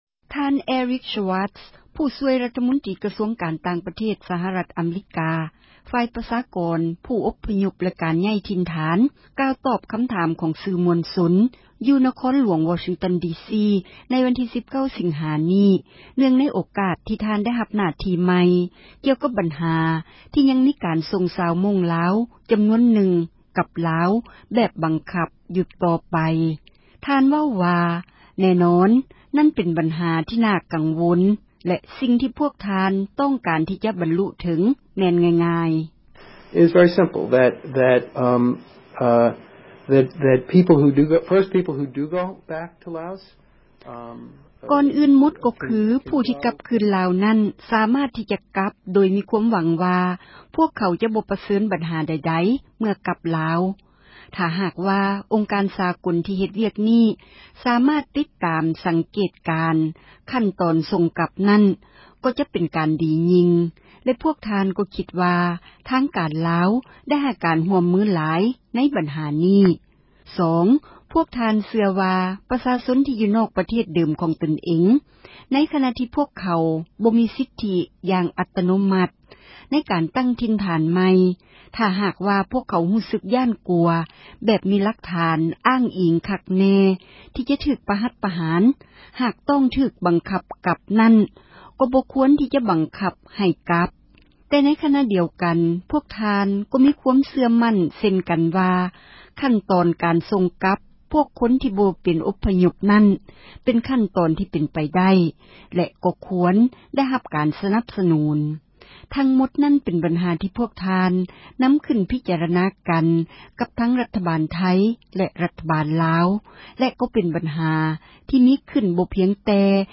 ທ່ານ Eric Schwartz ຜູ້ຊ່ວຍຣັຖມົນຕຣີ ກະຊວງການຕ່າງປະເທດ ສະຫະຣັຖ ອະເມຣິກາ ຝ່າຍປະຊາກອນ ຜູ້ອົພຍົບແລະ ການຍ້າຍຖີ່ນຖານ ກ່າວຕອບຄໍາຖາມ ຂອງຊື່ມວນຊົນ ຢູ່ທີ່ນະຄອນຫລວງ Washington DC ໃນວັນທີ19 ສີງຫາ ນີ້.